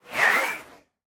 Minecraft Version Minecraft Version 1.21.5 Latest Release | Latest Snapshot 1.21.5 / assets / minecraft / sounds / mob / breeze / idle_air4.ogg Compare With Compare With Latest Release | Latest Snapshot
idle_air4.ogg